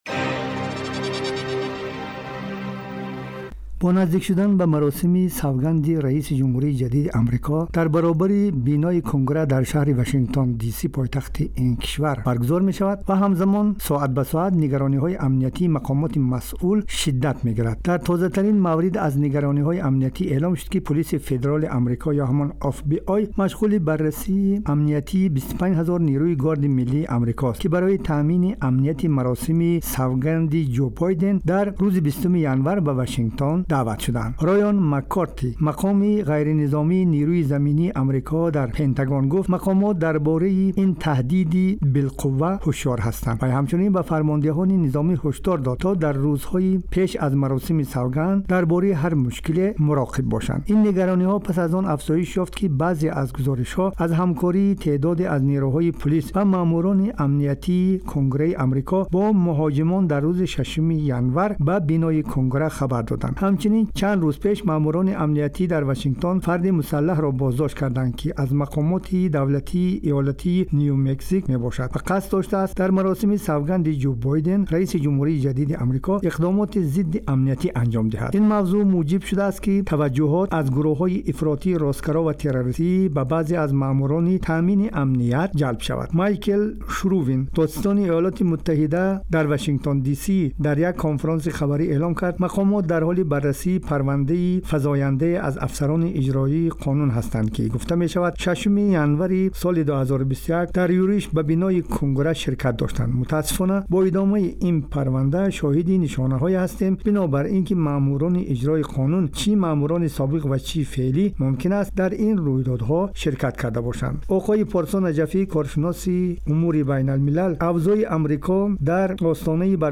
Гузориши вижа: Таърихитарин маросими савганди раёсати ҷумҳурӣ дар Амрико